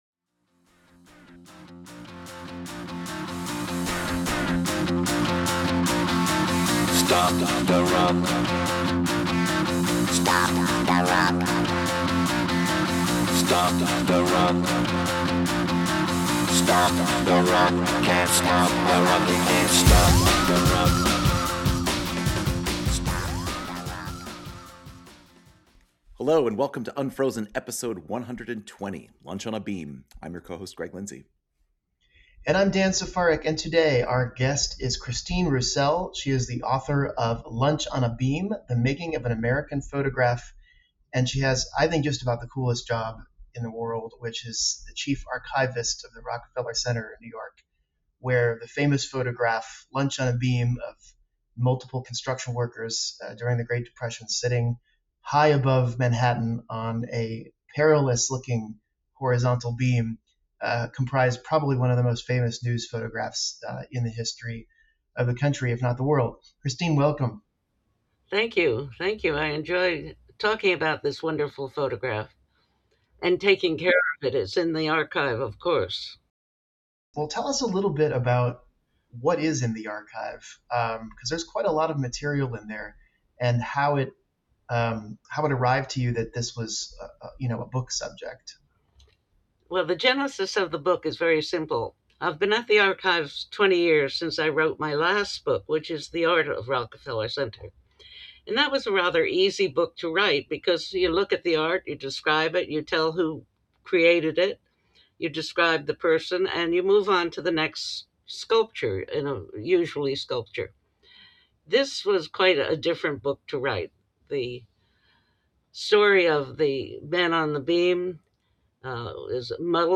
Listen to the Unfrozen interview.